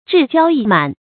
志驕意滿 注音： ㄓㄧˋ ㄐㄧㄠ ㄧˋ ㄇㄢˇ 讀音讀法： 意思解釋： 形容得意驕傲。